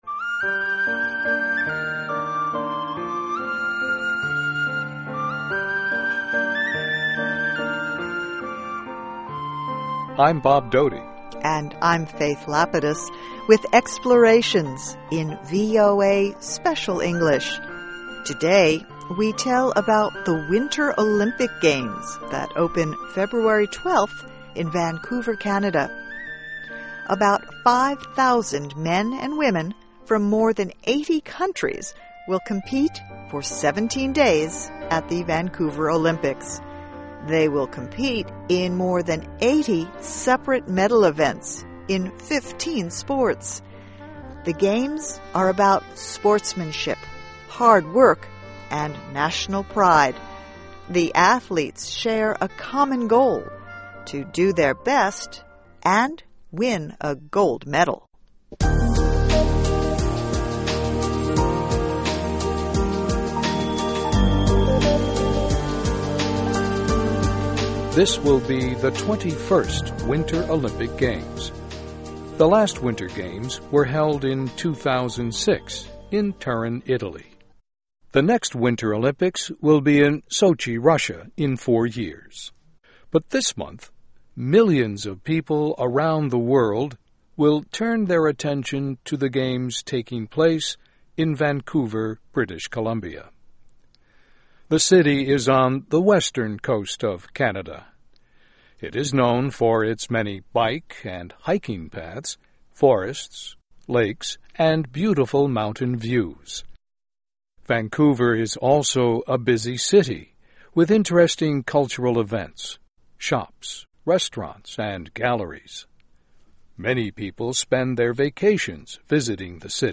English Listening Practice.